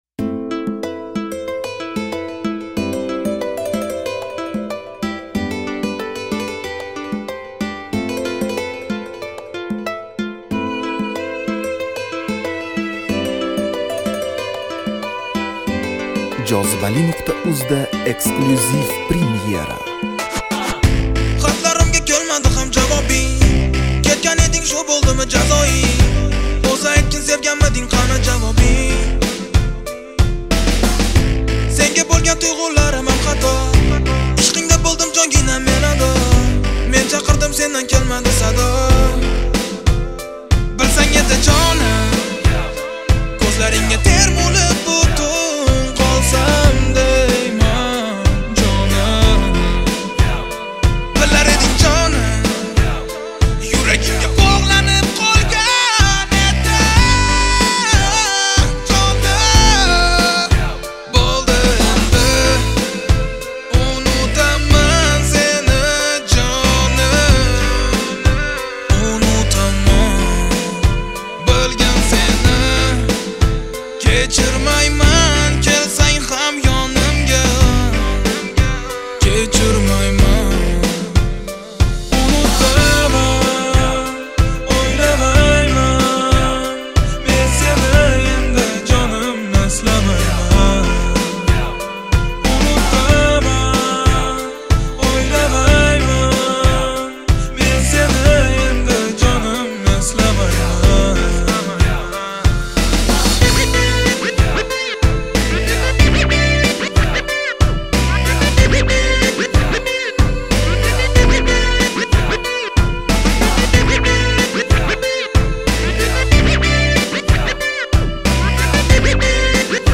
Узбекский рэп